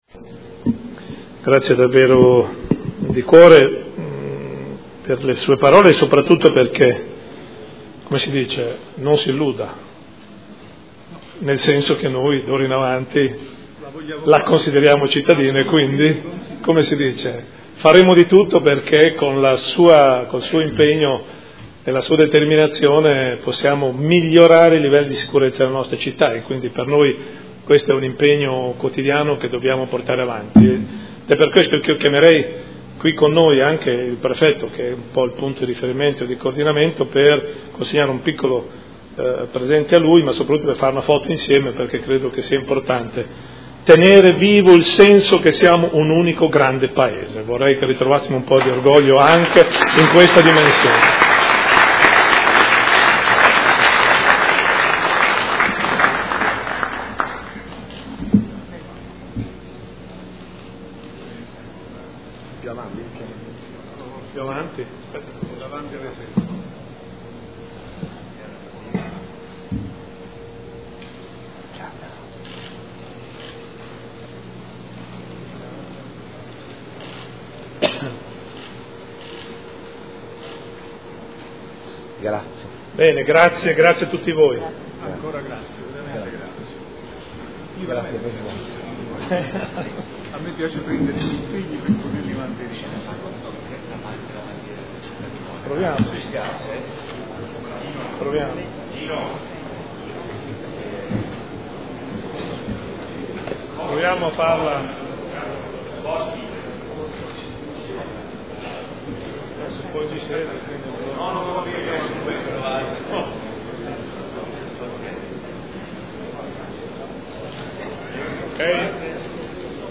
Seduta del 21/09/2015.